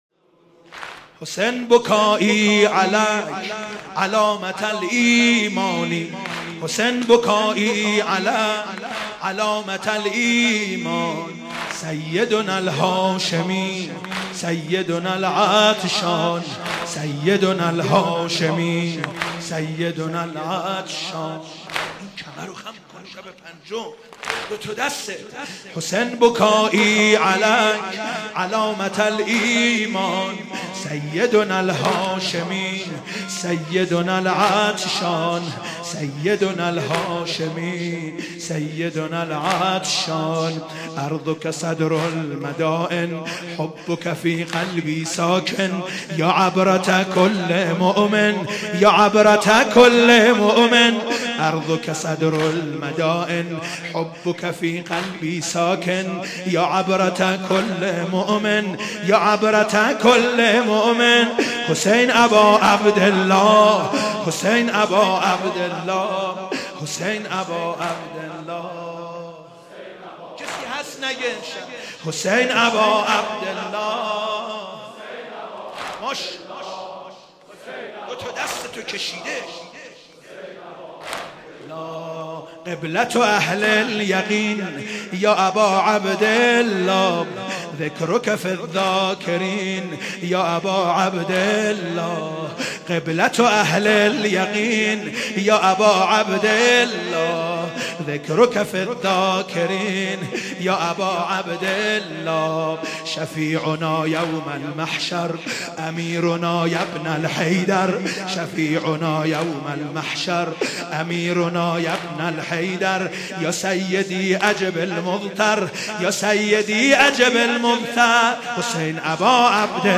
تهران مسجد امیر
واحد عربی